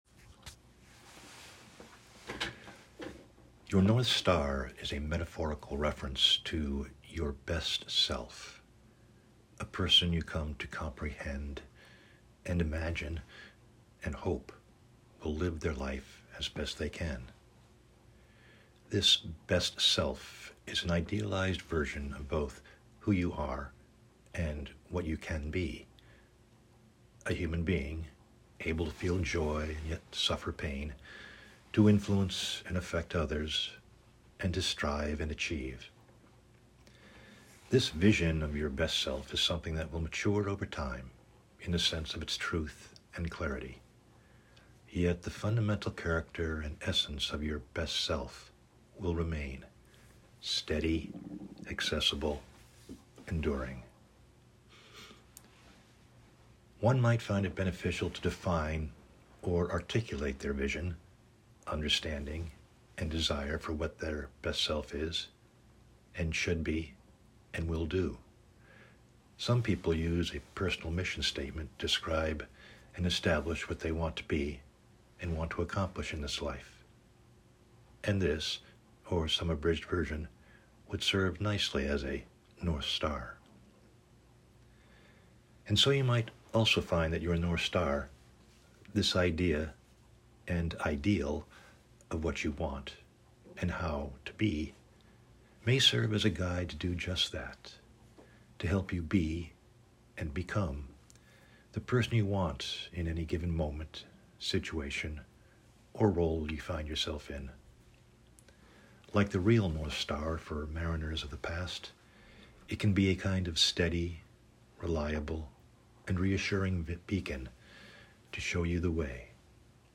Audio Presentation: Your North Star…